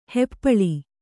♪ heppaḷi